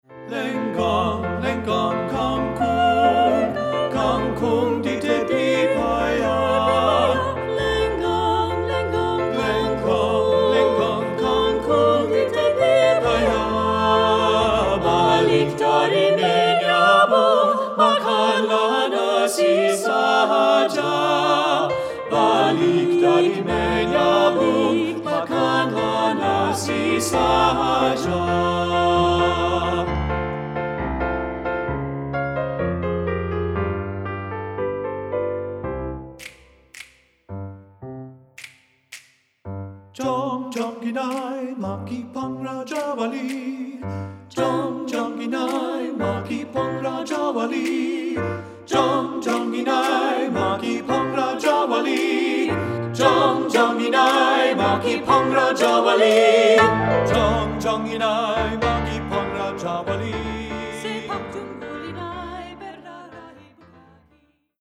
Choral Multicultural
SATB